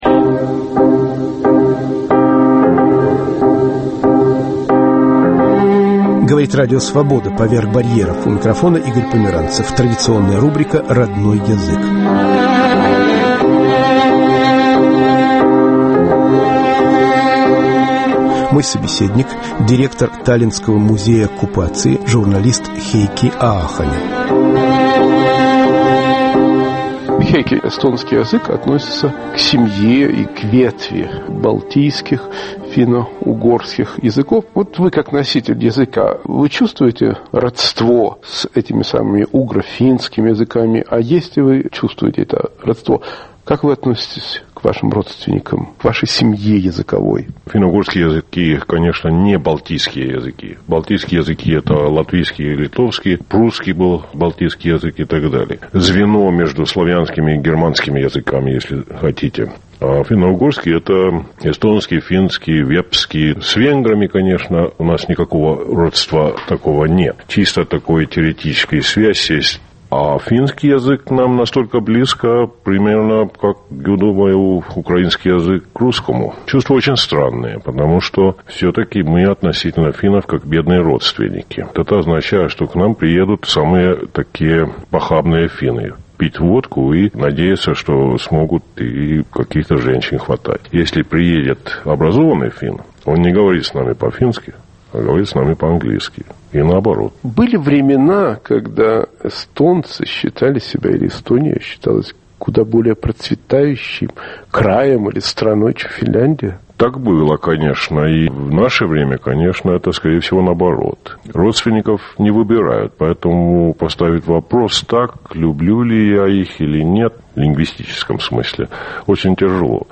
"Родной язык" Разговор о современном эстонском языке.